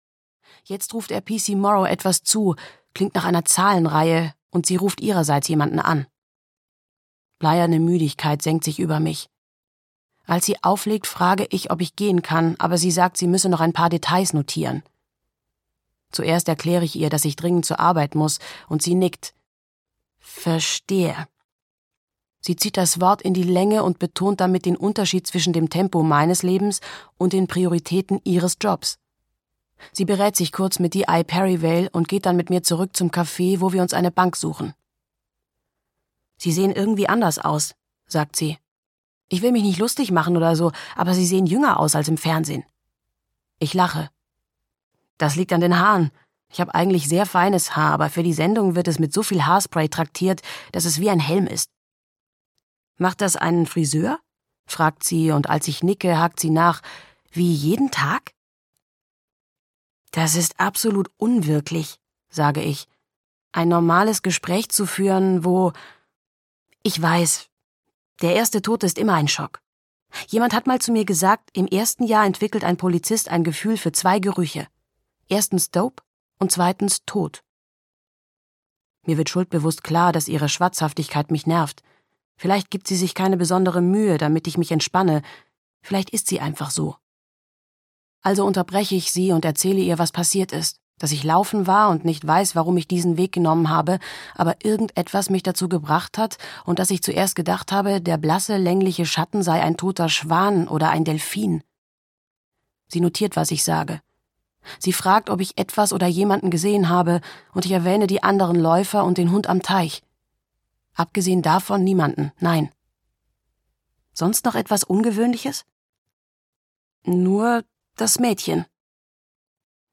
Ich bin unschuldig - Sabine Durrant - Hörbuch